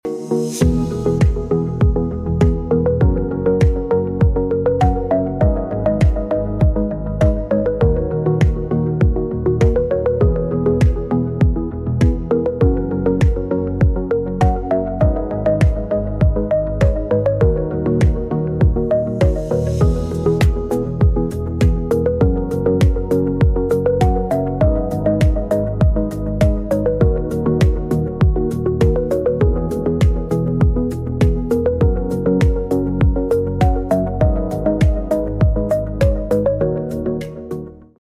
High Precision 20 110 mm HDPE sound effects free download